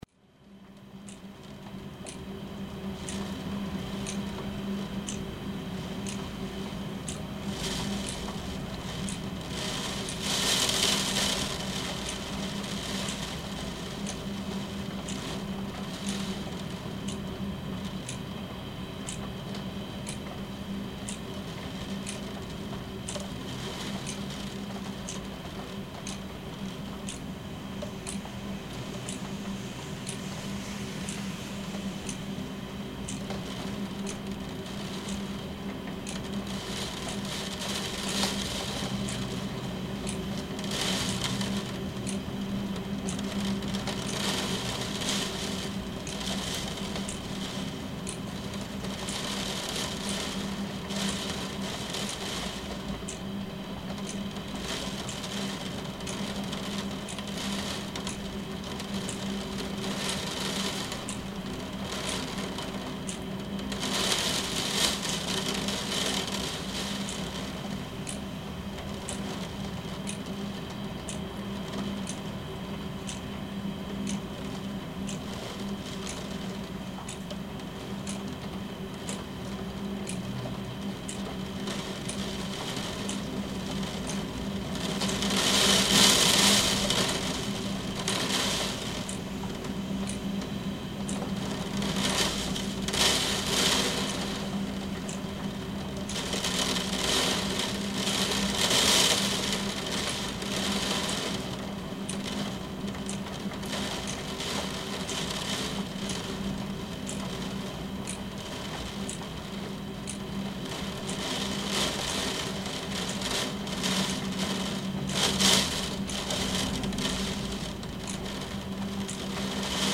Rok og rigning
En þó, það er eitthvað rólegt við það að hlusta á rok og rigningu berja rúðurnar.
Stillti ég upp tveimur ME64 hljóðnemum í svefnherberginu og tók upp í 16Bit / 44.1Khz. Veðrið var að mestu gengið niður þegar upptakan fór fram um kl 14:30. Heyra má í upptökuni háværan grunnsón. Er hann að mestu tilkominn frá bílaumferð en bæði Miklabraut og Reykjanesbraut eru í u.þ.b. kílómeters fjarlægð. Rokið í trjánum á þó eitthvað í þessum hávaða líka. Þar sem hljóðnemarnir voru nálægt rúðum þá má greinilega heyra hávaðan að utan “sóna” með holum hljómi á milli tvöföldu rúðunnar. Þá má líka heyra í vekjaraklukkuni sem var á náttborðinu þar nærri.
rok-og-rigning-wav0071.mp3